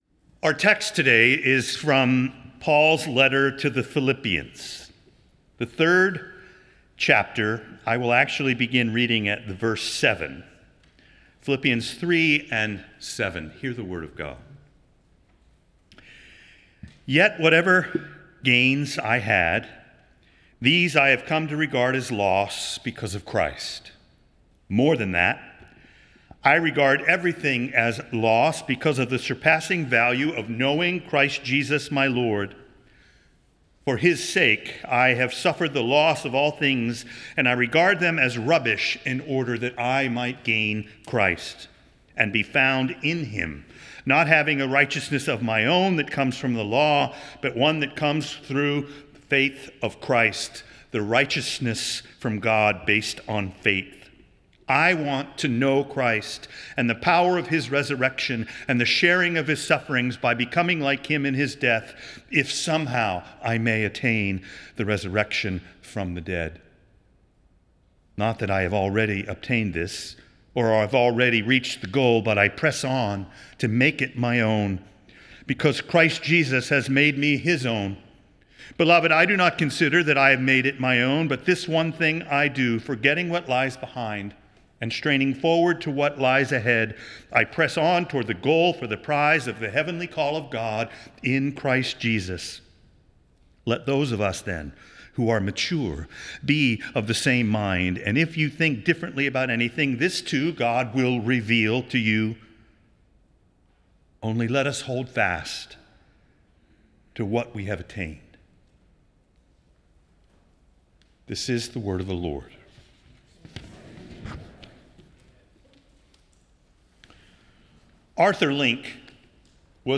Nassau Presbyterian Church Sermon